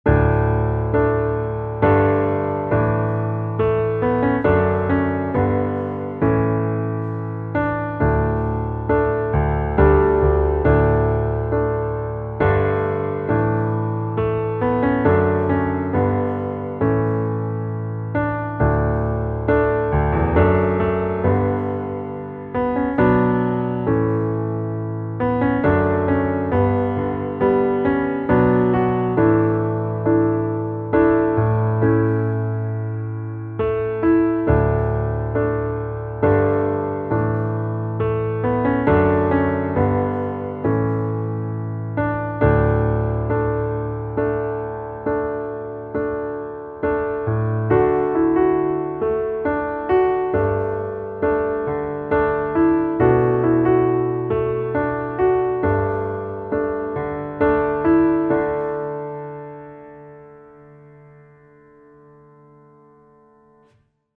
underscores for contemporary worship
piano demo